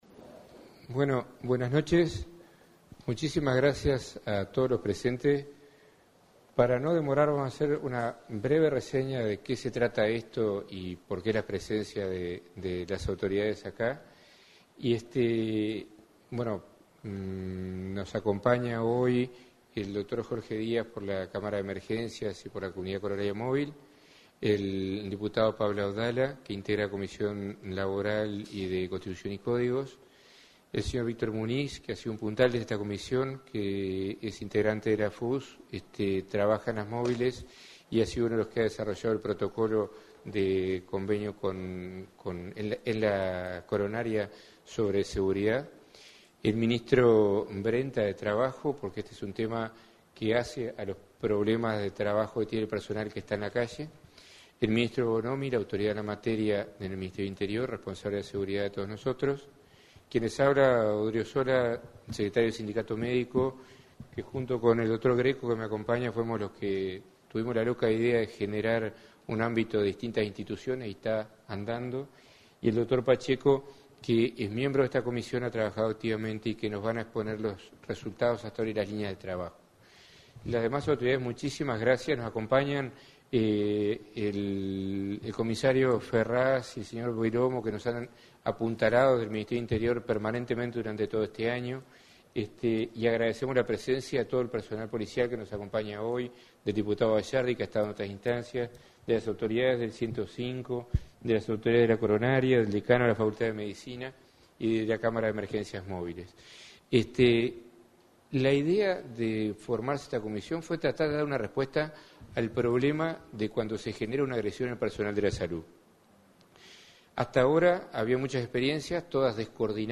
El evento contó con la presencia de los ministros Bonomi y Brenta quienes destacaron el trabajo de la Comisión
La Comisión Multinstitucional por la Seguridad del Personal de Atención Extrahospitalaria (COMSEPAE) celebró su primer año de trabajo con un evento en el Sindicato Médico. Allí los integrantes de la Comisión realizaron evaluaciones del trabajo que se ha hecho a lo largo del año.